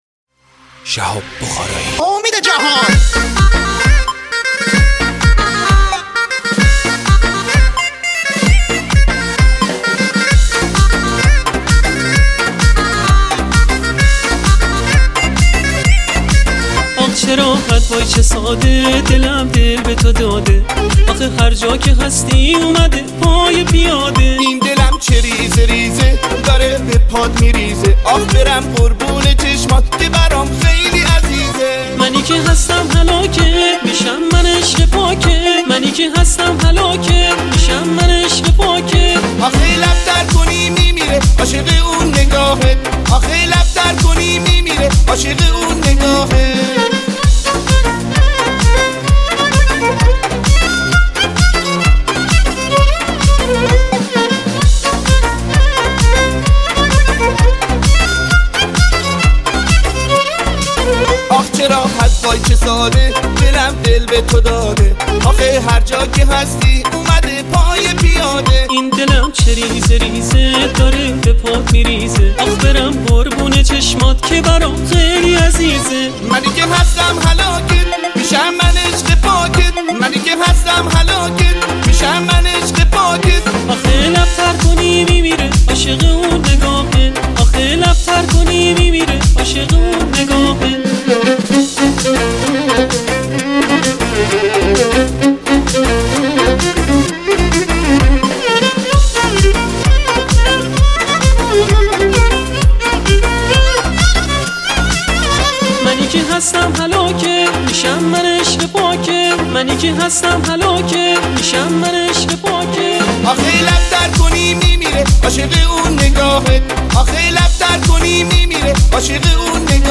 آهنگ های شاد ویژه شب یلدا